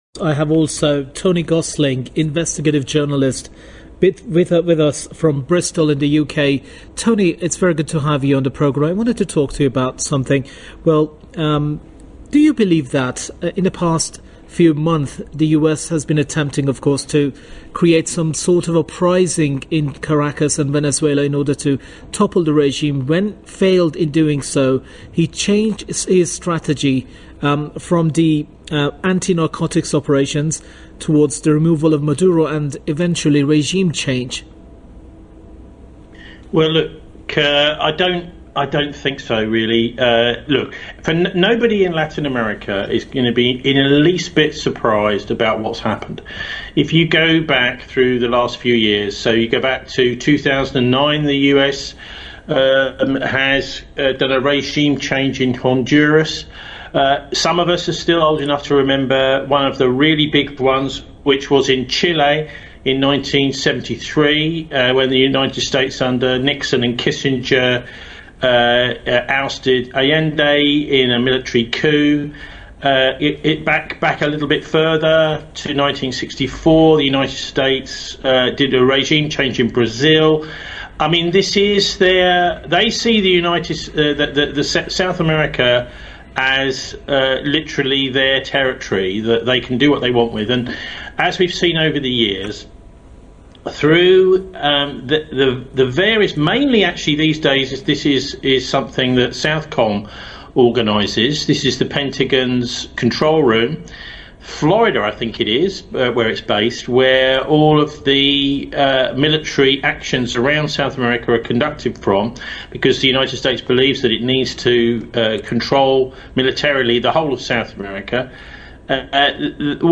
Rolling News Coverage